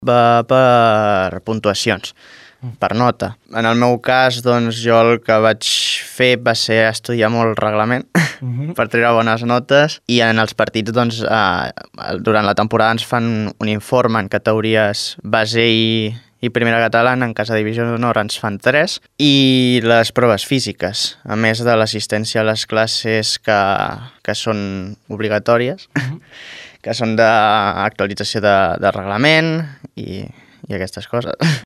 Ell mateix comentava els requisits i els paràmetres que ha hagut de complir per aconseguir l’ascens.